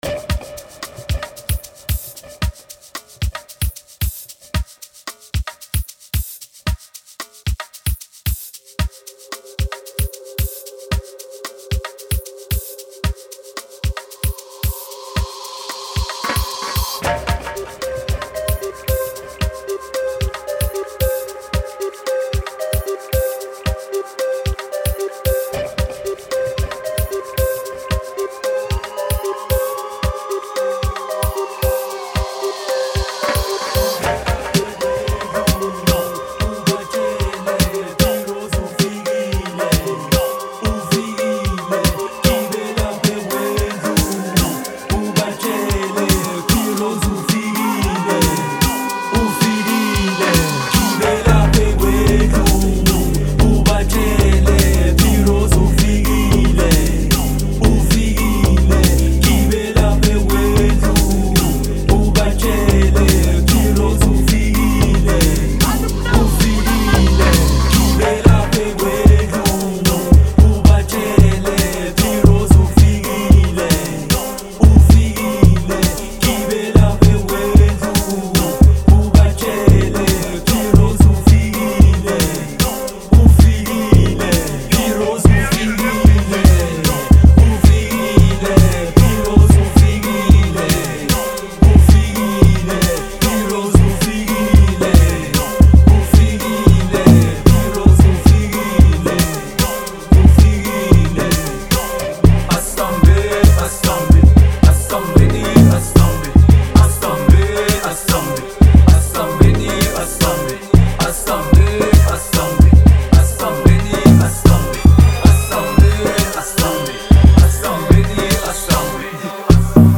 05:59 Genre : Amapiano Size